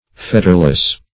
Fetterless \Fet"ter*less\, a. Free from fetters.
fetterless.mp3